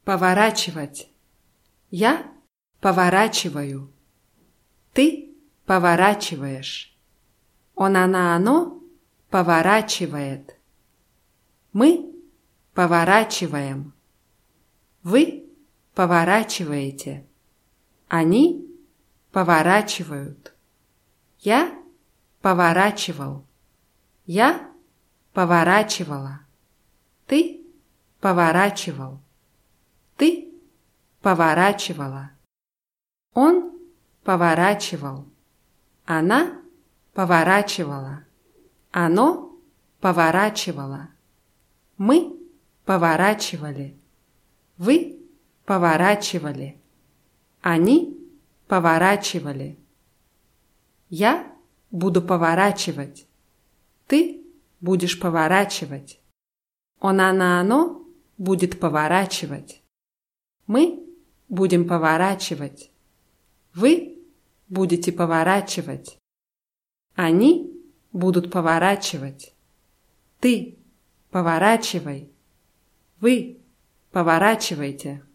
поворачивать [pawarátschiwatʲ]